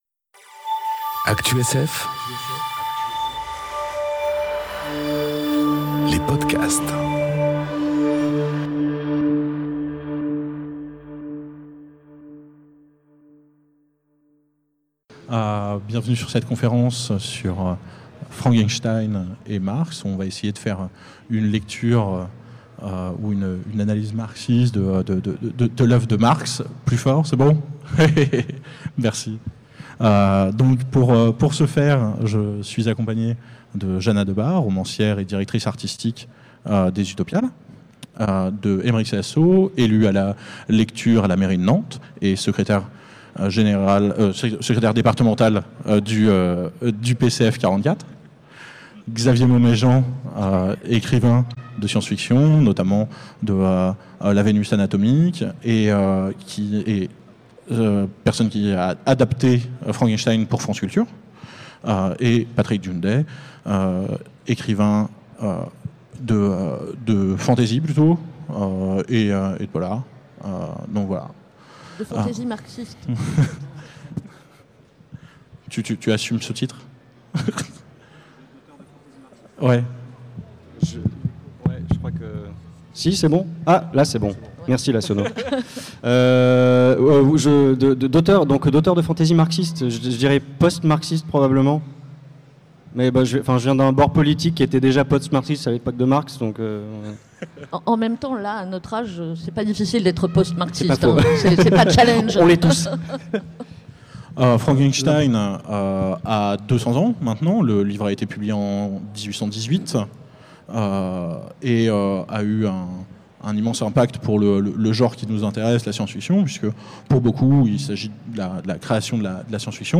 Conférence Frankenstein vs Marx enregistrée aux Utopiales 2018